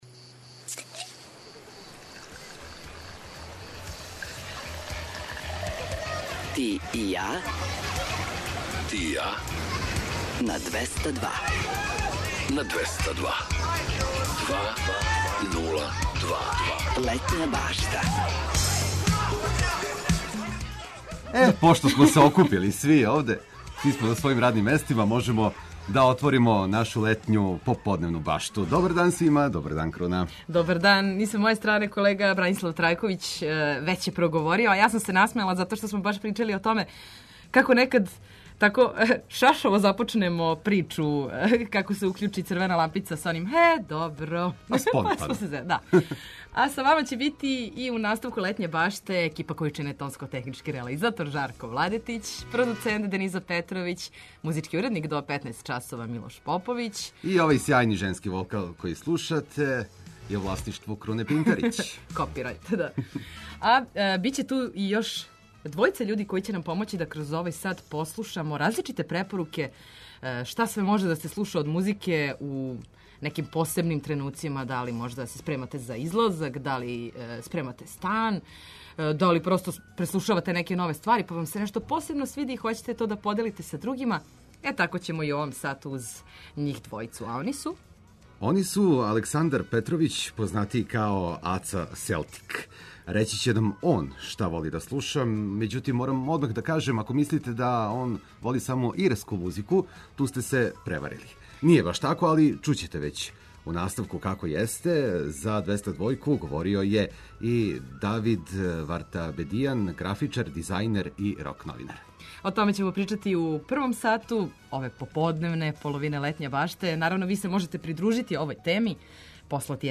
Очекују вас и приче о филмовима и песмама, као и обиље добре музике.